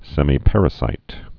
(sĕmē-părə-sīt, sĕmī-)